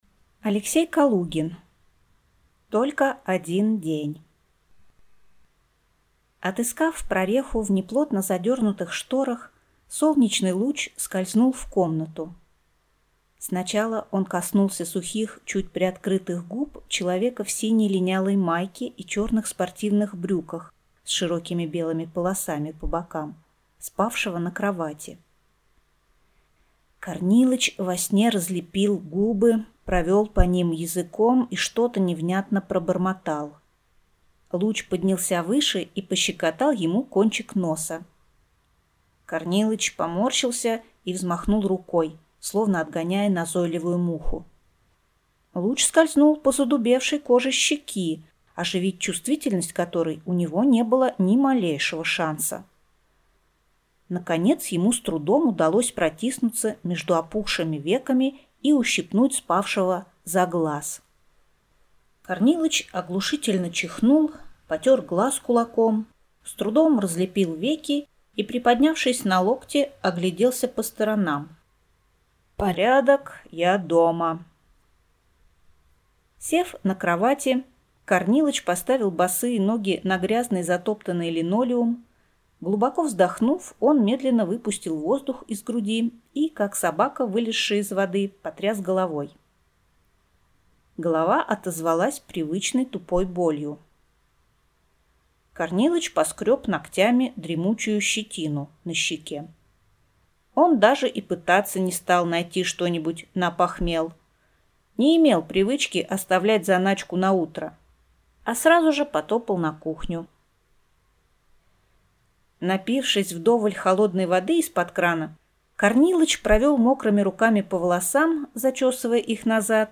Аудиокнига Только один день | Библиотека аудиокниг
Прослушать и бесплатно скачать фрагмент аудиокниги